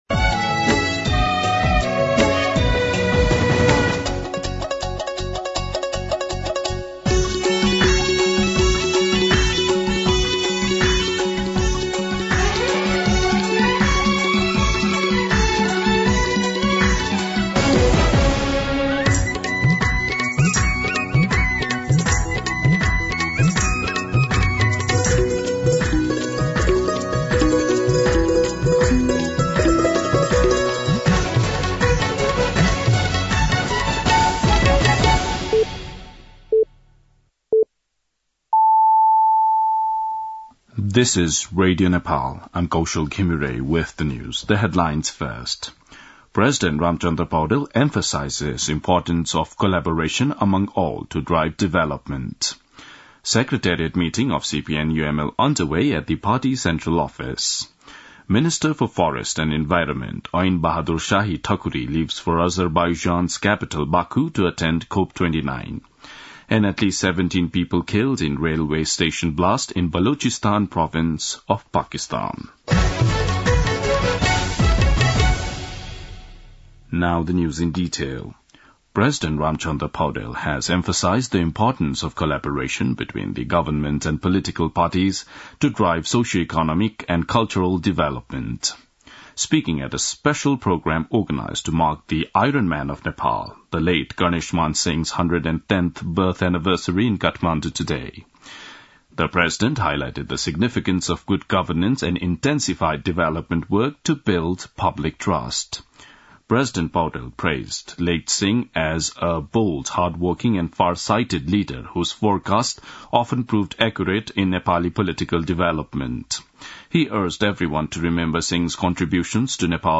दिउँसो २ बजेको अङ्ग्रेजी समाचार : २५ कार्तिक , २०८१
2pm-news-.mp3